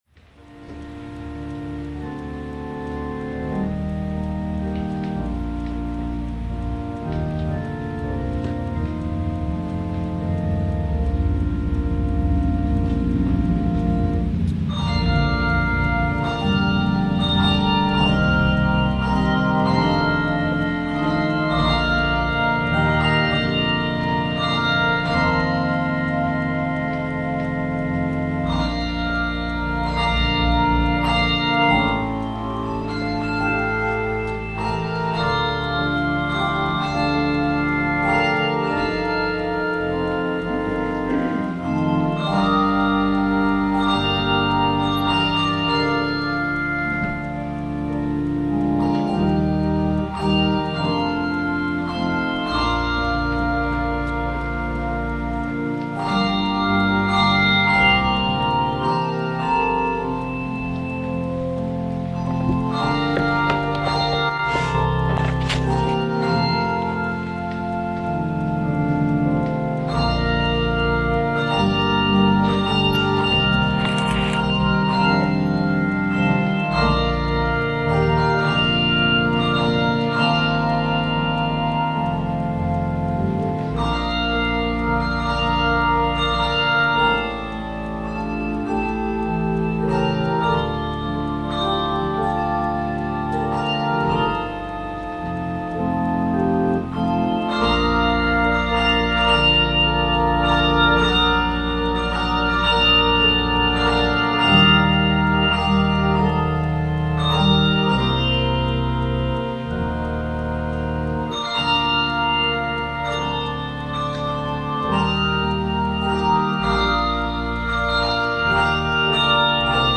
Download   Music / Offertory: “Let Ther Be Peace on Earth” – Performed by The Hand Bell Choir – arr. Freeman – 9/11/2016